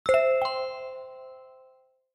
alert.0SNbz7dJ.mp3